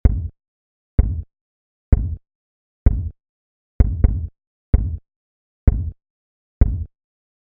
Paso 2: El Kick
A continuación, vamos a bajar el tono de la muestra, para que la fundamental sea un poco más grave y sea más similar a la frecuencia en la que golpearía un bombo de verdad.
En la imagen siguiente puedes ver el sencillo ritmo a blancas que hemos programado para este bombo, con un redoble, en un clip de 4 compases de duración.
ejemplo-audio-kick.mp3